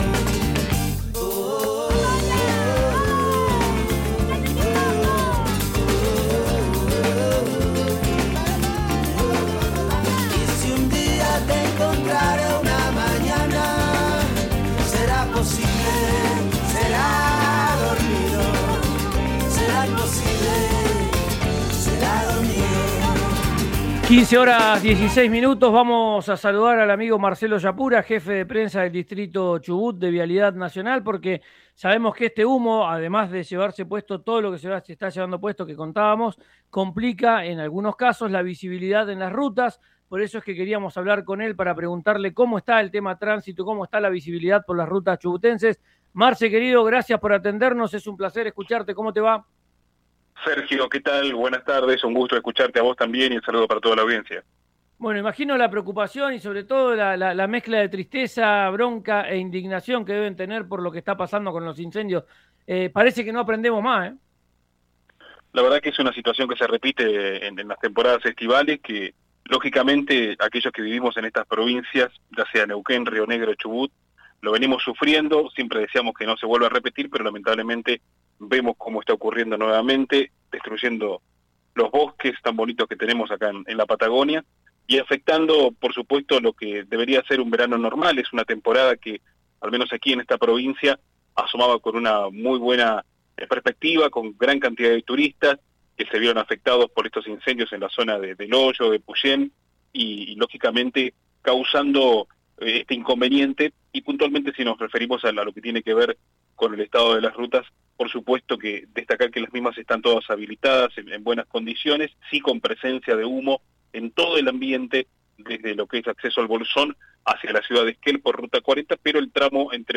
La situación fue detallada en una entrevista con RÍO NEGRO RADIO, donde se precisó que el sector entre El Bolsón y Bariloche presenta «condiciones normales».